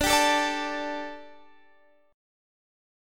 Dsus4 Chord (page 3)
Listen to Dsus4 strummed